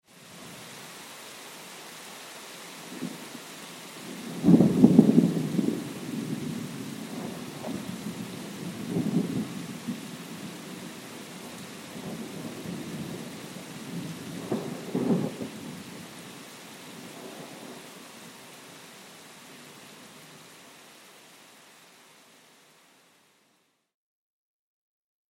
دانلود آهنگ رعدو برق 6 از افکت صوتی طبیعت و محیط
جلوه های صوتی
دانلود صدای رعدو برق 6 از ساعد نیوز با لینک مستقیم و کیفیت بالا